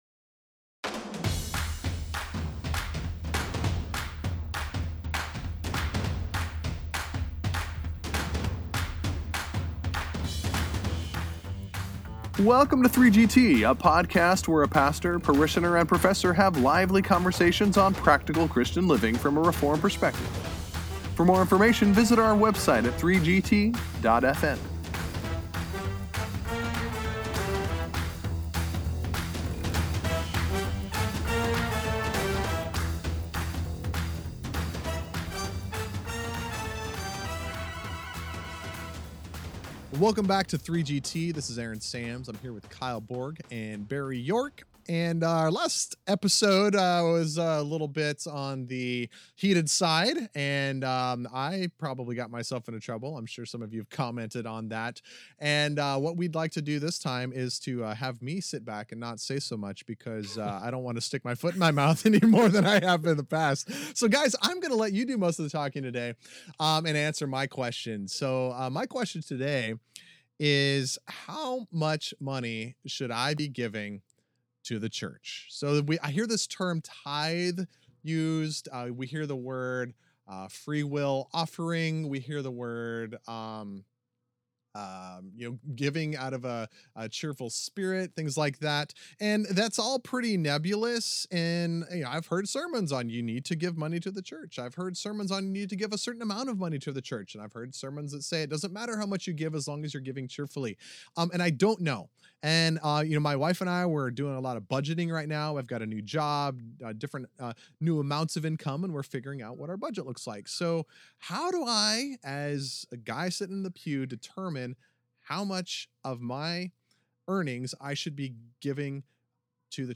But not when the pastor and professor tangle!